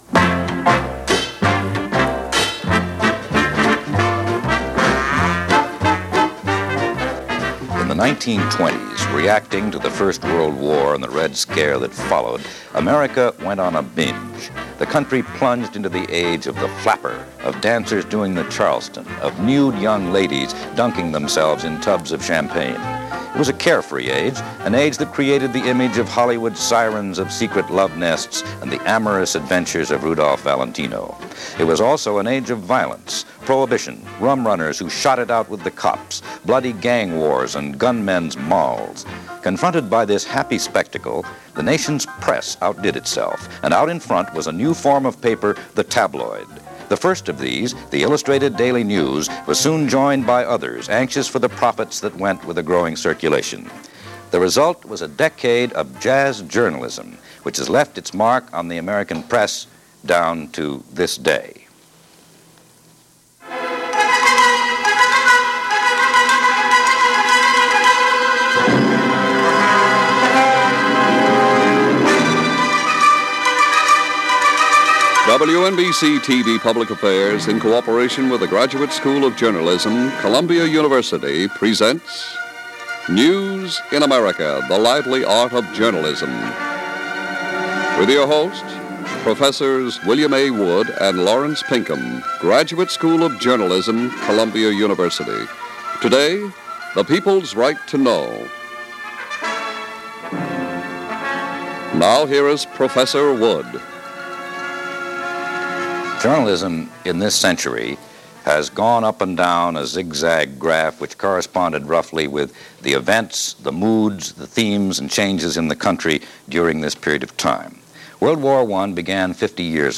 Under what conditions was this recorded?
Although this program, originally aired in 1965, discusses the era of the Tabloid as something that grew and prospered as a result of the 1920s, some evidence indicates the need to sensationalize the news goes back much further and may just be part of our National DNA.